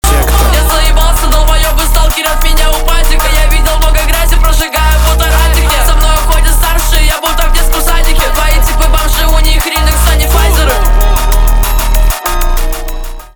Рэп рингтоны
битовые , басы , качающие
жесткие